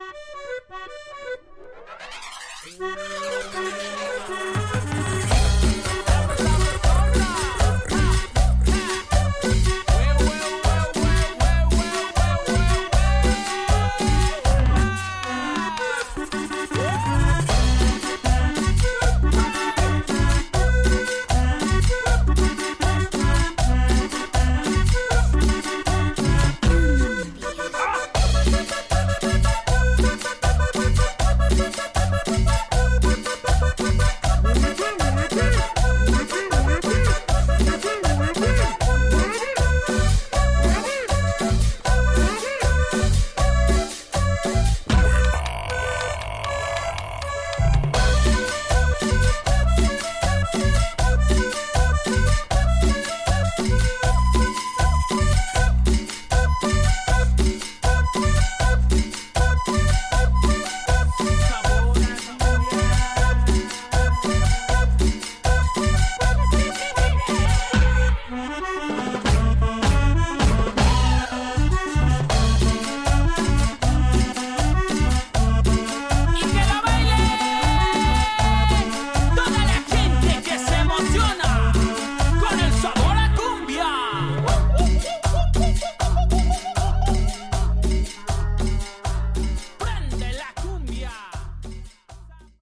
Tags: Cumbia , Sonidero , Mexico
Pura cumbia wepa say no more folks.